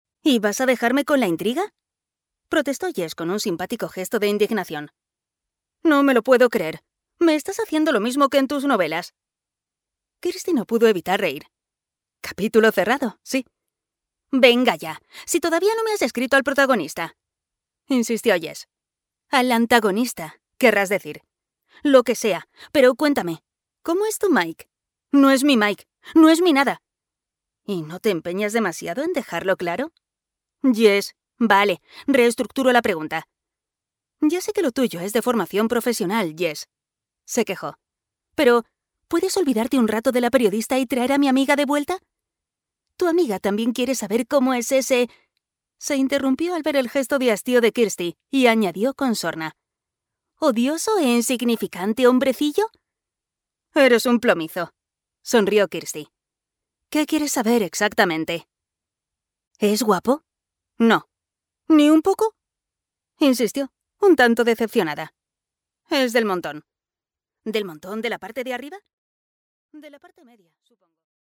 Audiolibro Te odio, pero solo a ratos (I Hate You, But Only Sometimes)